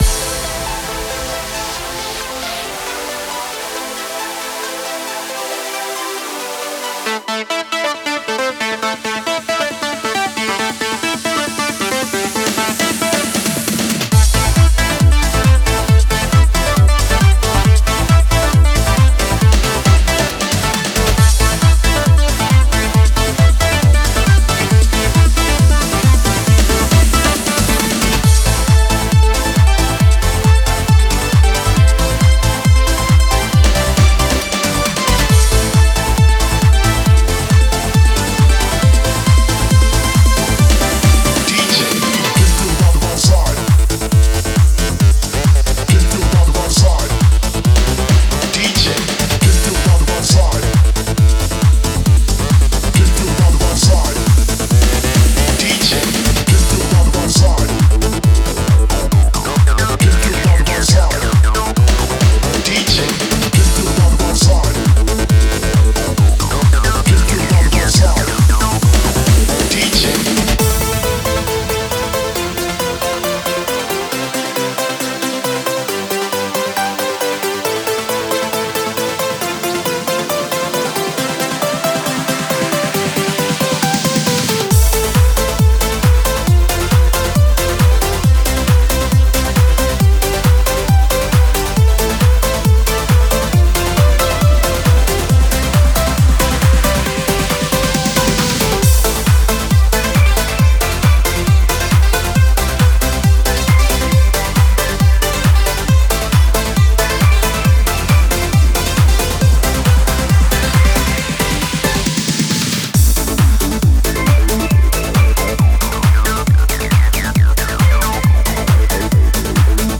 Type: Spire Midi Templates Samples
90s Classic Eurodance